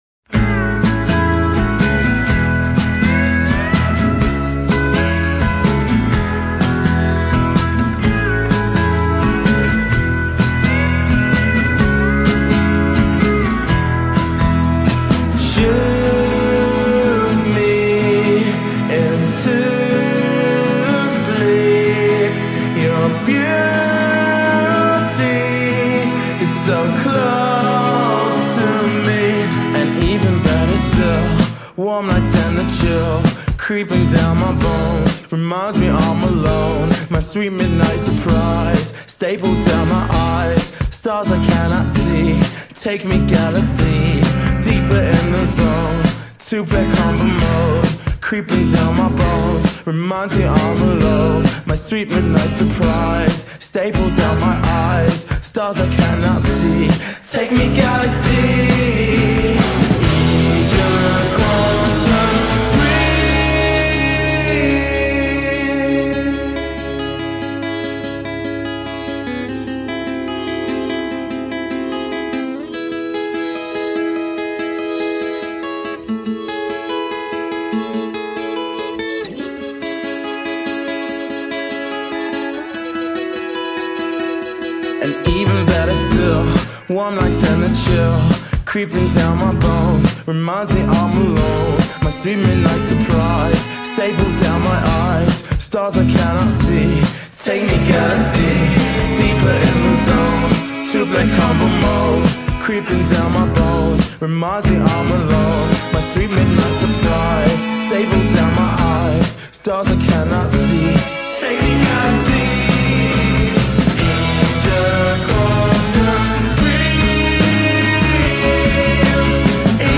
folk-prog di 10 minuti